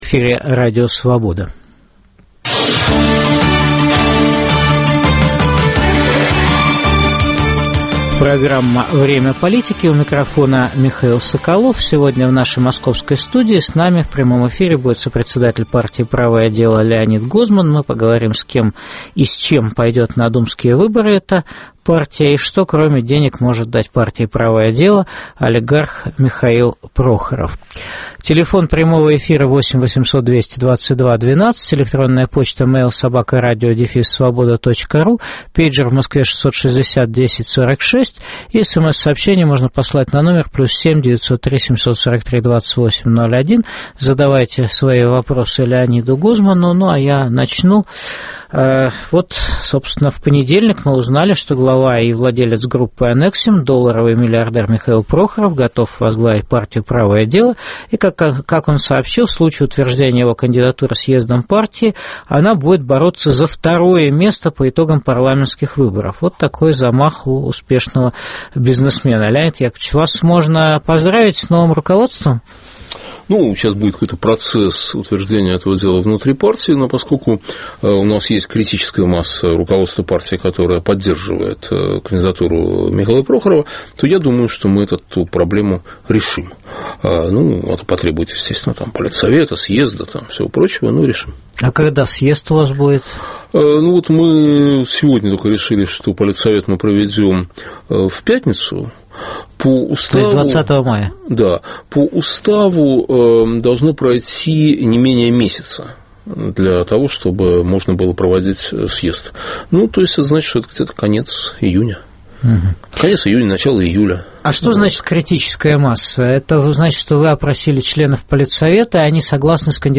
Что кроме денег может дать партии олигарх Михаил Прохоров? В прямом эфире выступит сопредседатель партии "Правое дело" Леонид Гозман.